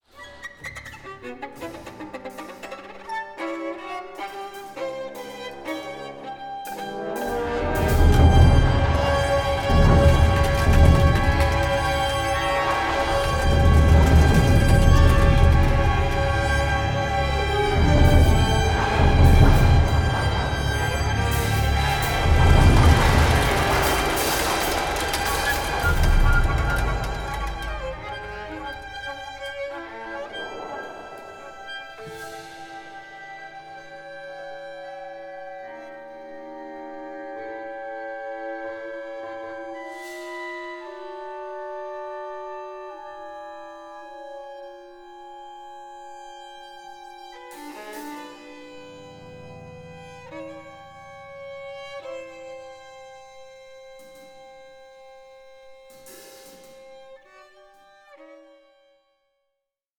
4) Lento (soloist plays offstage at the beginning) 6:21
A COSMIC, ADVENTUROUS PAIRING OF VIOLIN CONCERTOS